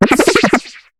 Cri de Pérégrain dans Pokémon HOME.